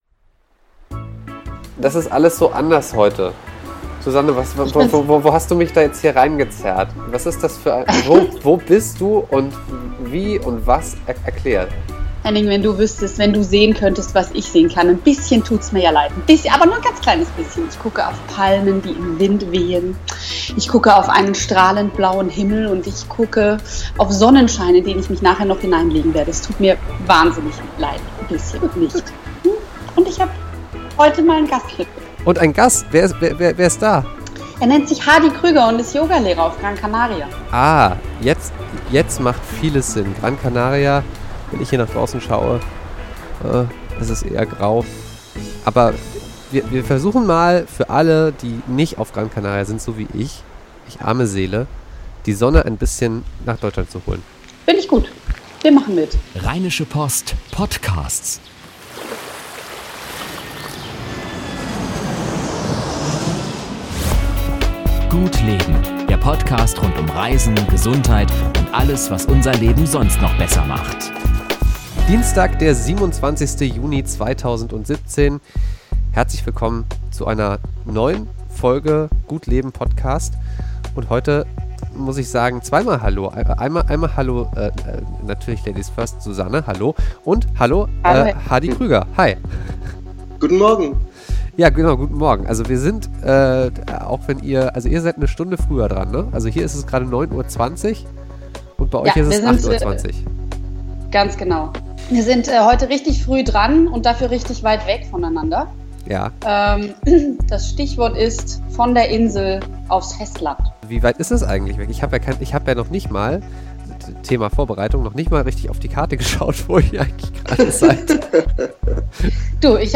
Intro-Geräusche
Intro-Musik